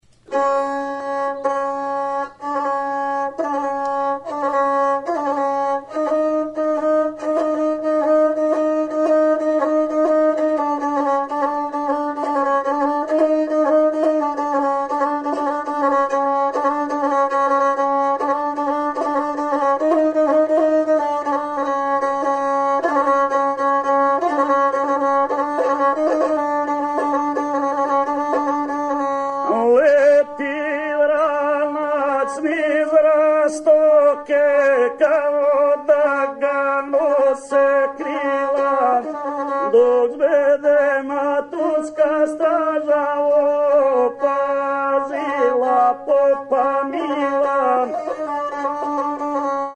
Cordes -> Frottées
(LIVE)